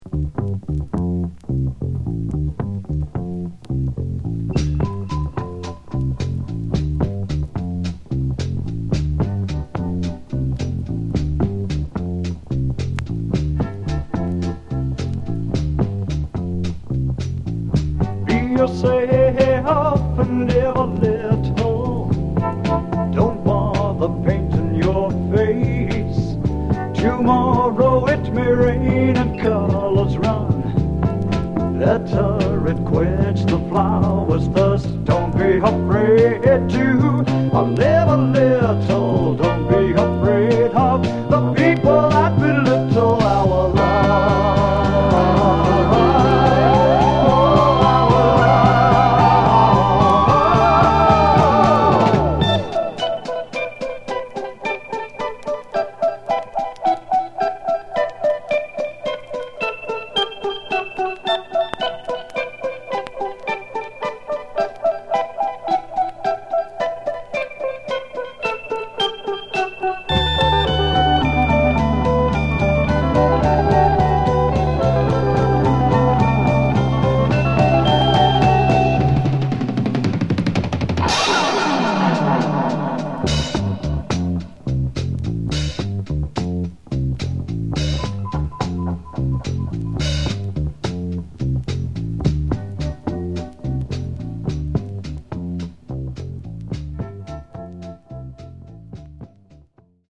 Genre: Garage/Psych
An interesting and highly worthwhile sonic experiment.